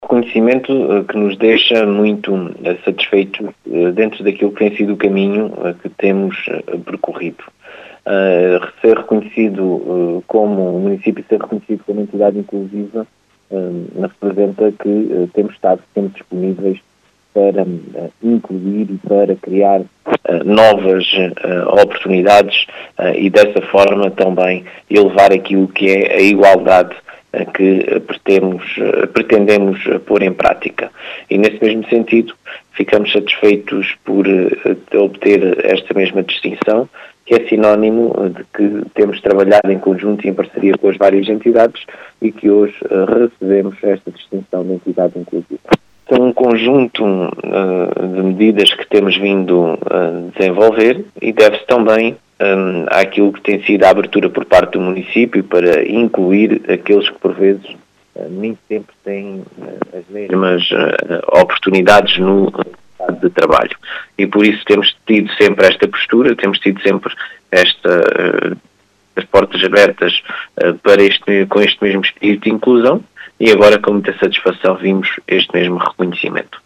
As explicações foram deixadas por Marcelo Guerreiro, presidente da Câmara Municipal de Ourique.
Marcelo-Guerreiro-1.mp3